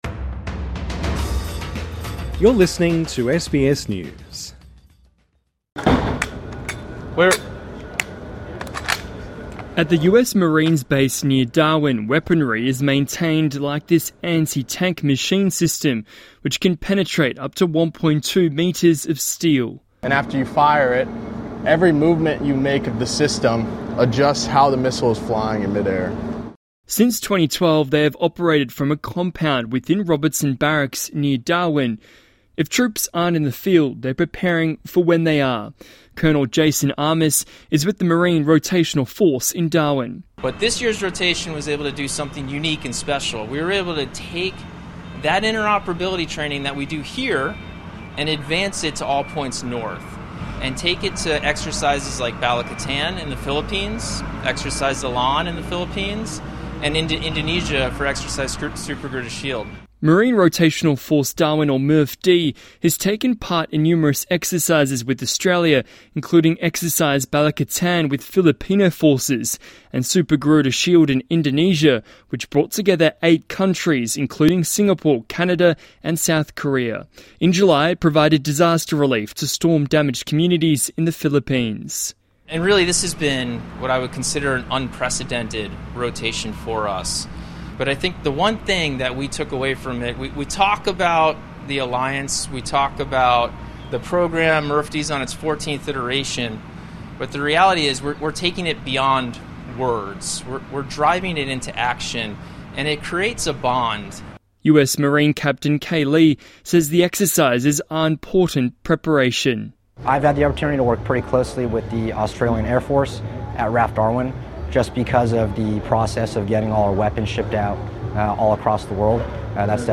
(Upsot of gun being loaded on the ground) At the US Marines base near Darwin, weaponry is maintained like this anti-tank machine system, which can penetrate up to 1.2 metres of steel.